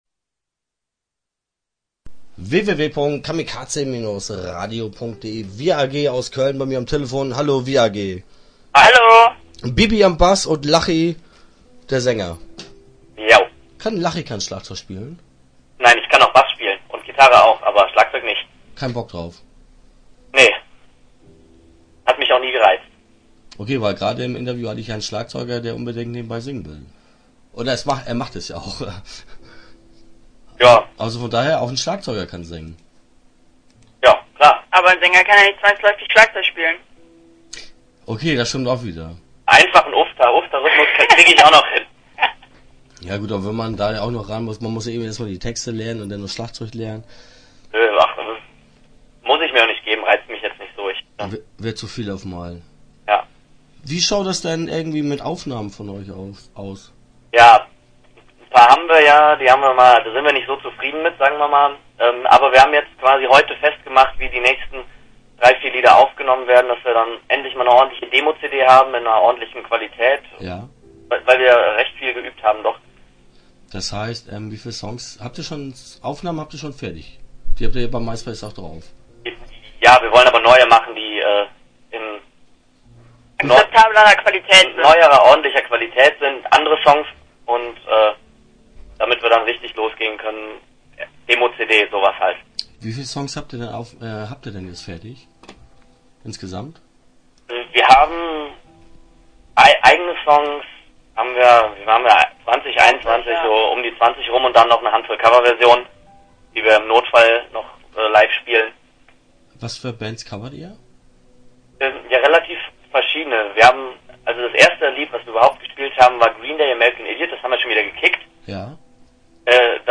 Interview Teil 1 (9:14)